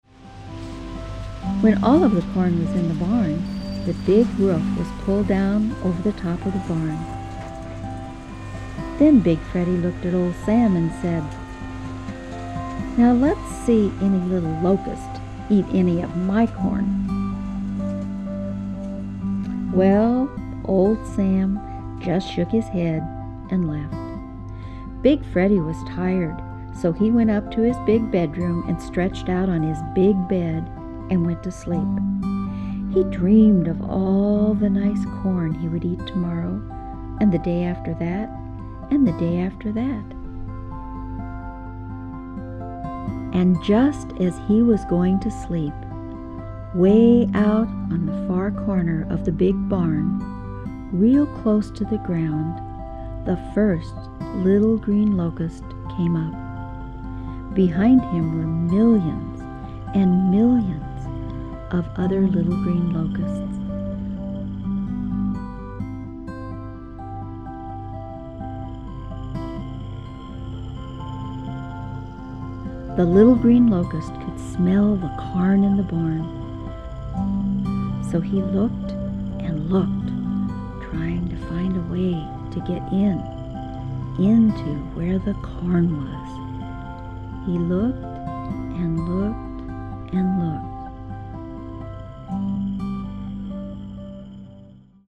Zvuková stopa 2 obsahuje jemné zvuky oceánského příboje (růžový šum) a Hemi-Sync®.
Anglické verbální vedení
Pohádka pro děti na dobrou noc, spánek.